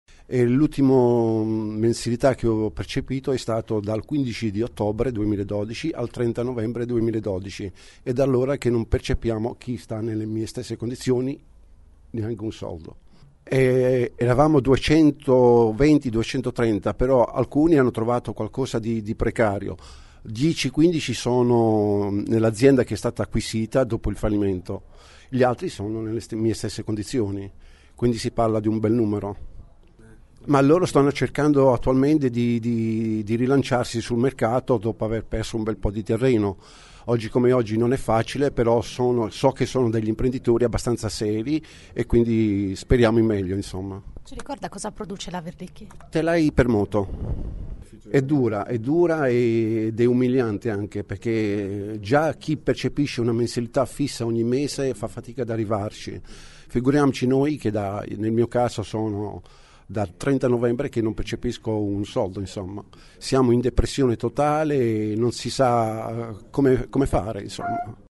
“E’ dura e umiliante”, racconta un operaio della Verlicchi, che ha ricevuto l’ultima busta paga il 30 novembre. Lavora da 40 anni, è in cassa integrazione da 3, ultimamente in deroga.